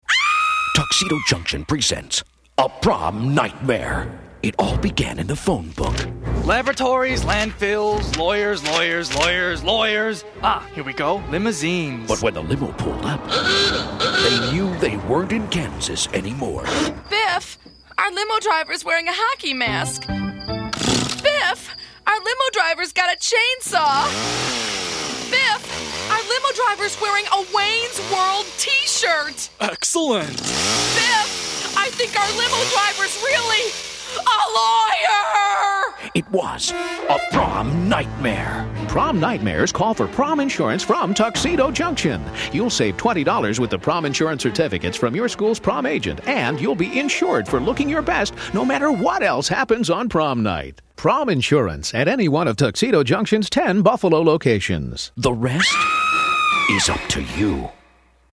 Through a series of four 60-second radio spots, we created a memorable campaign geared towards teens. Each spot featured a humorous "prom nightmare," encouraging kids to purchase prom insurance from Tuxedo Junction.